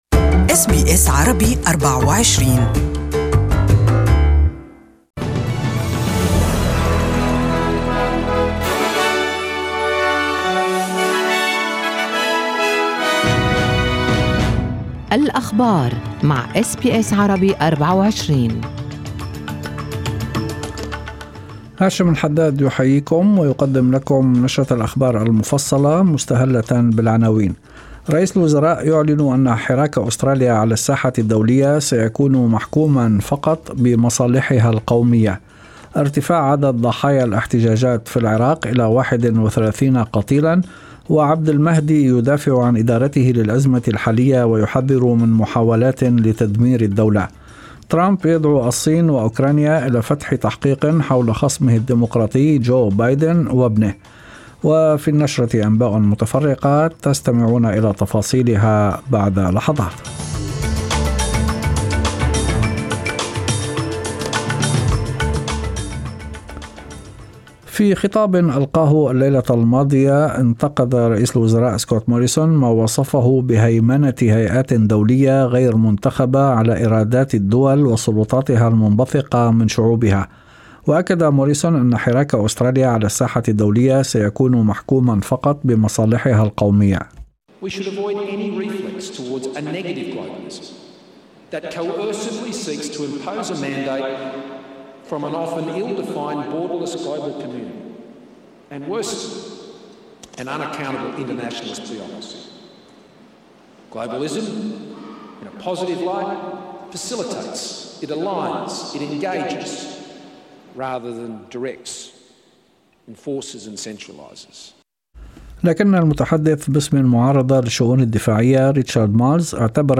Evening News: 11 cases diagnosed with measles in Perth